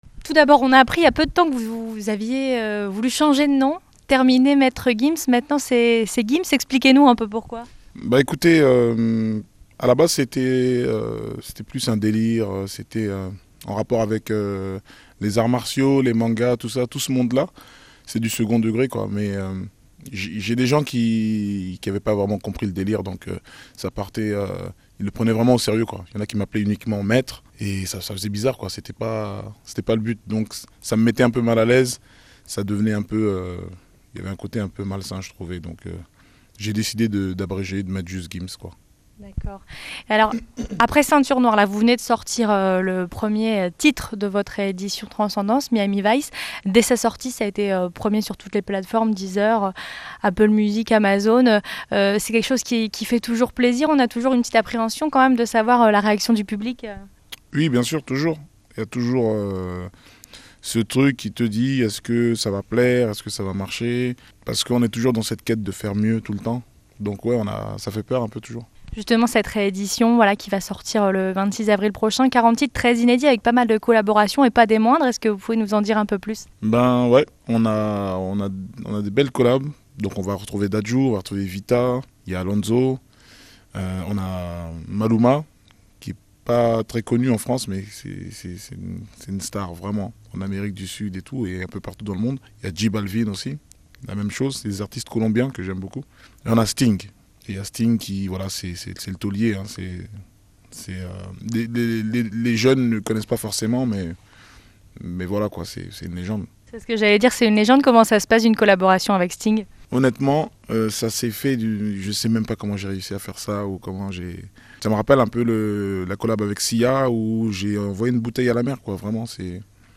Avant son concert au Kursaal ce jeudi soir, Gims a accordé une demi-heure en toute intimité à dix auditeurs de RADIO6 pour répondre à leurs questions et faire quelques photos avec eux.